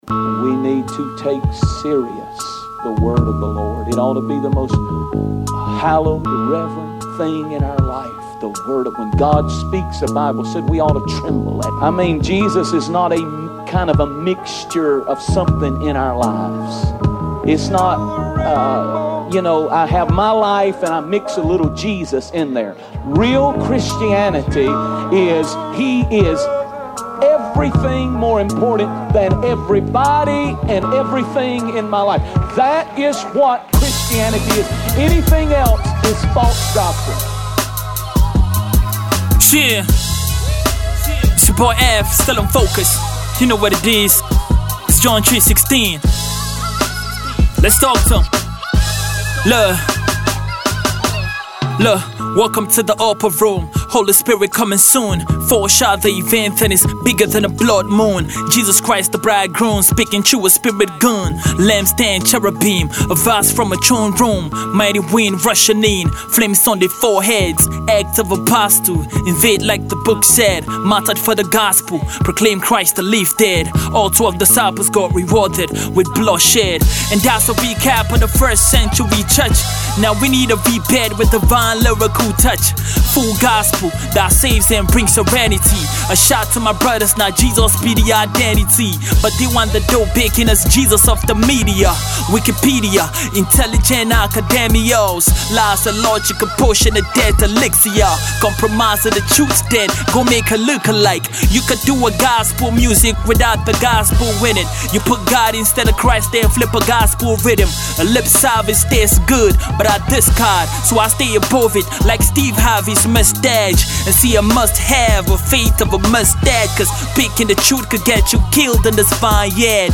vintage hip-hop